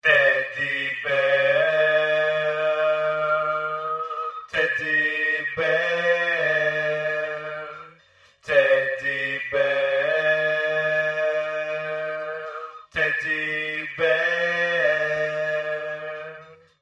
Vocals (3), 263 KB
tb_vocals_3.mp3